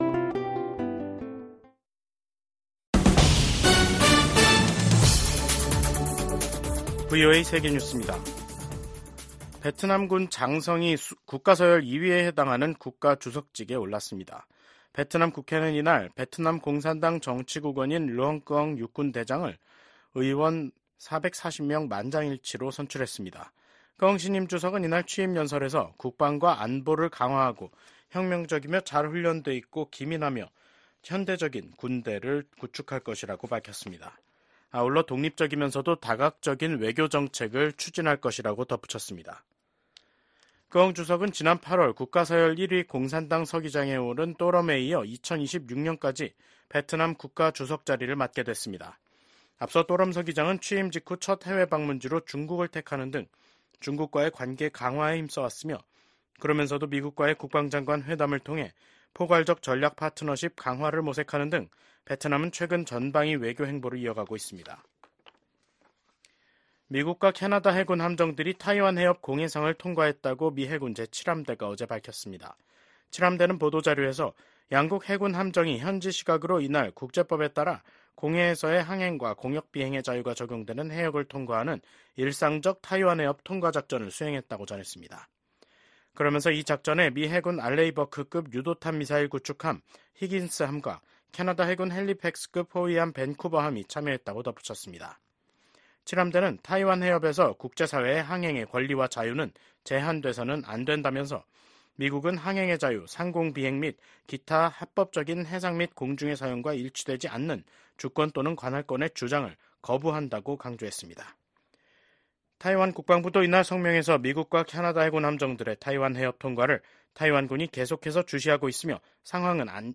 VOA 한국어 간판 뉴스 프로그램 '뉴스 투데이', 2024년 10월 21일 3부 방송입니다. 북한이 대규모 병력을 우크라이나 전장에 투입하기로 했다는 한국 정부의 발표에 대해 미국 정부가 중대한 우려의 입장을 밝혔습니다. 북한에 인력을 요청할 수밖에 없다면 이는 러시아의 절망의 신호일 것이라고 지적했습니다.